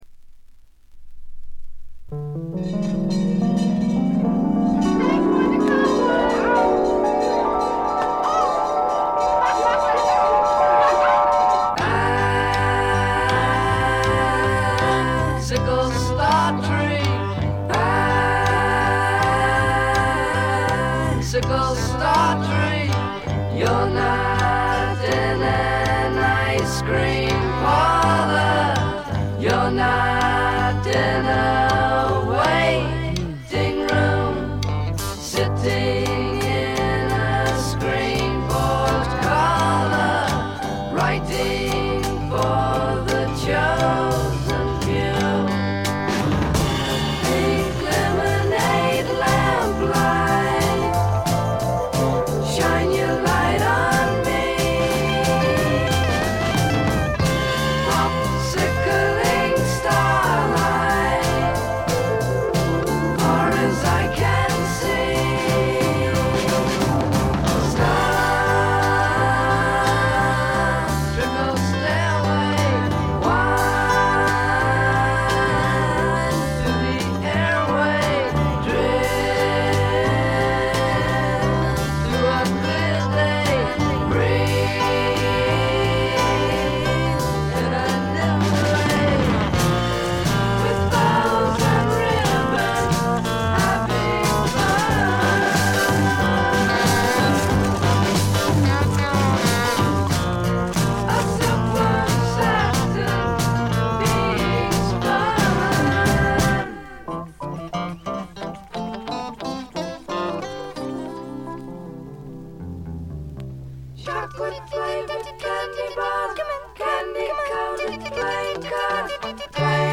軽微なバックグラウンドノイズ、少しチリプチ。
60年代ポップ・サイケな色彩でいろどられたサージェント・ペパーズな名作！！
試聴曲は現品からの取り込み音源です。